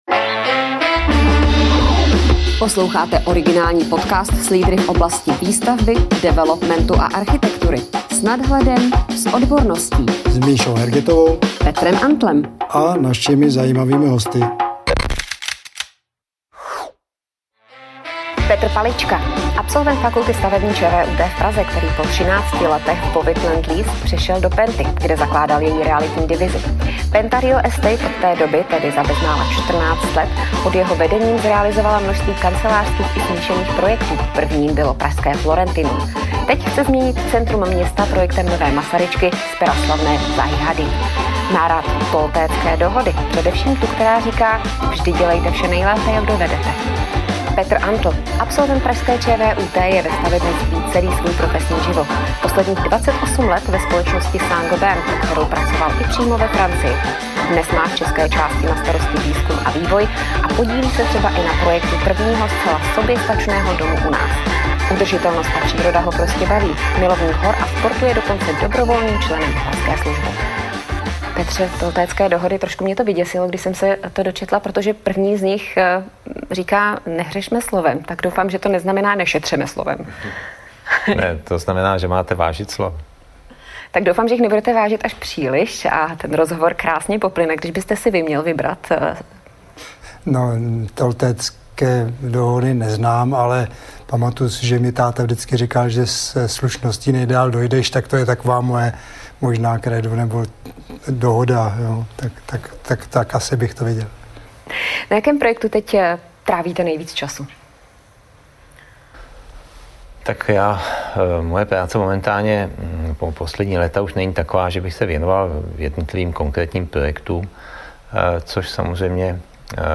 Diskuze